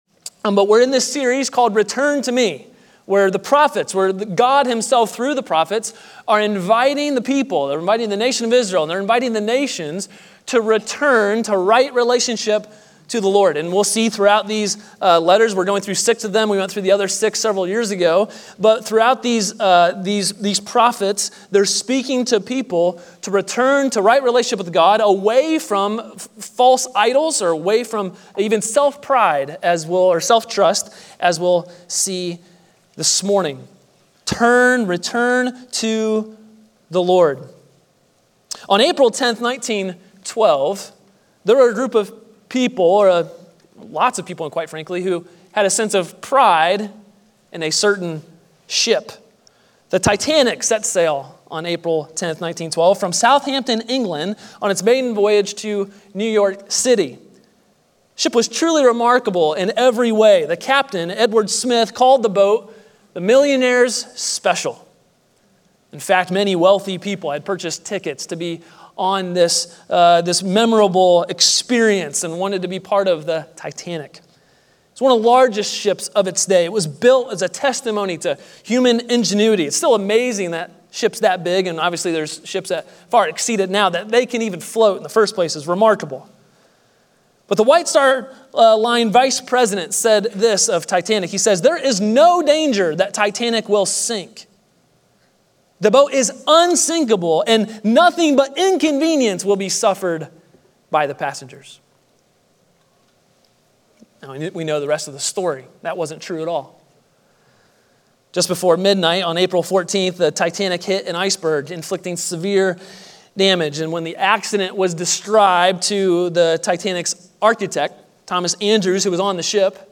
A sermon from the series "Return to Me."